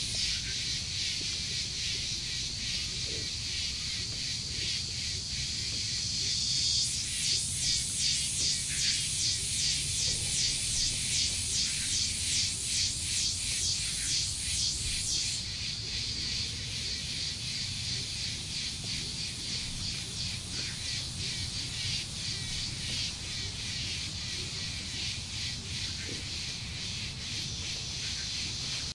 幽静的森林里有青蛙和鸟儿
描述：白天记录青蛙和鸟类在一个安静的森林。
标签： 森林 青蛙 青蛙 现场记录
声道立体声